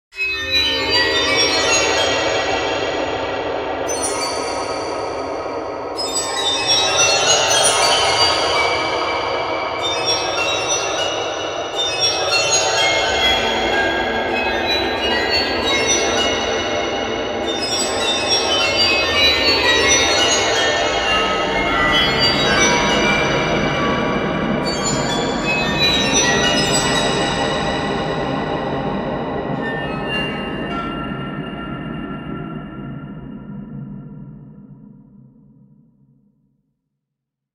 Spooky Background Fairy Dust Sound Effect
Description: Spooky background fairy dust sound effect. Sharp, unpleasant background tension sound. Perfect for boosting suspense and drama in any scene.
Spooky-background-fairy-dust-sound-effect.mp3